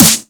edm-snare-44.wav